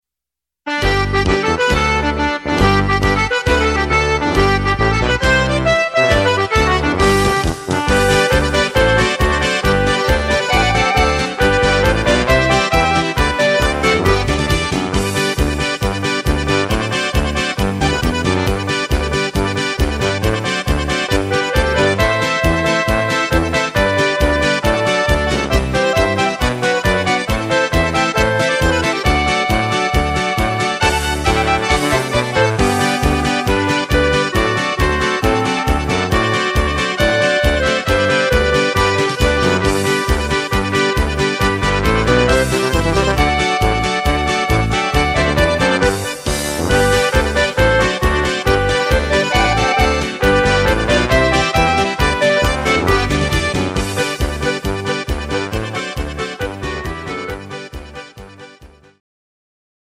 Oberkrainer Sound